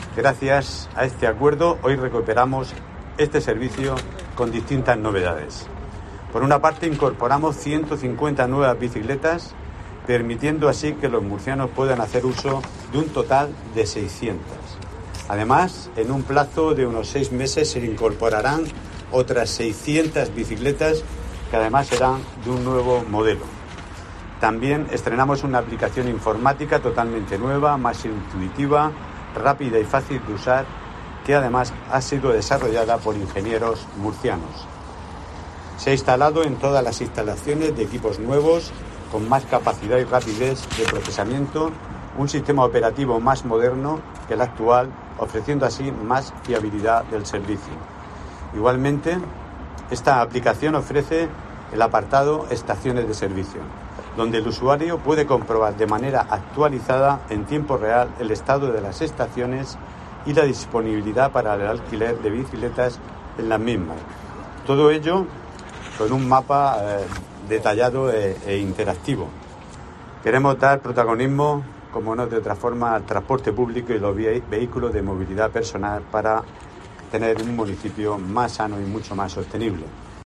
José Antonio Serrano, alcalde de Murcia